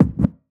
Perc - TurnTable.wav